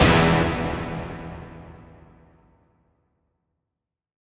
SOUTHSIDE_fx_orchestral.wav